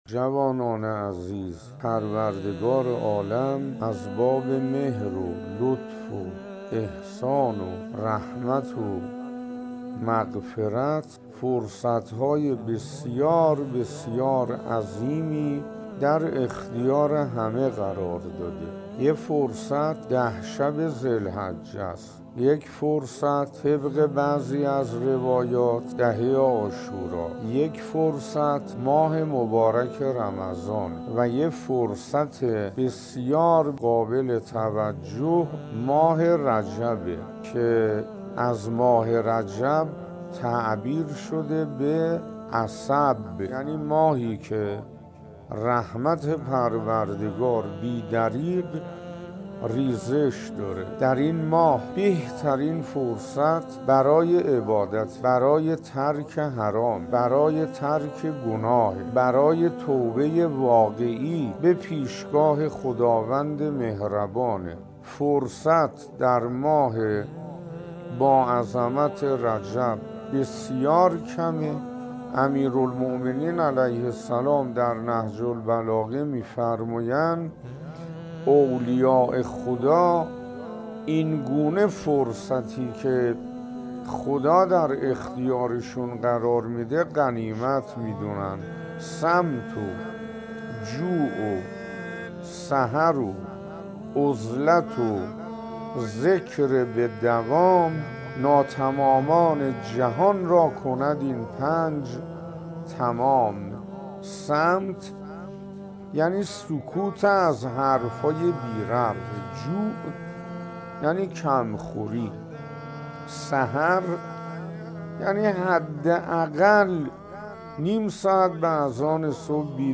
این ماه بهترین فرصت برای عبادت، ترک حرام، ترک گناه و توبه واقعی است. در ادامه بخشی از سخنرانی شیخ حسین انصاریان تقدیم مخاطبان گرامی ایکنا می‌شود.